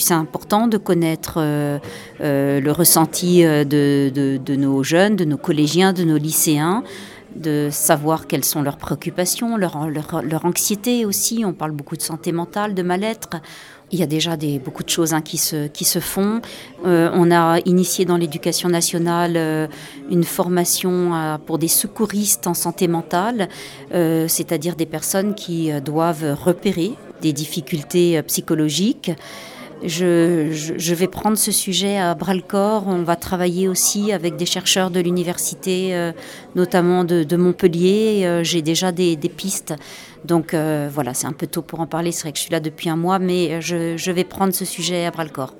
Santé mentale des lycéens : « Un sujet à prendre à bras-le-corps » explique Carole Drucker-Godard, rectrice académique d’Occitanie
Carole Drucker-Godard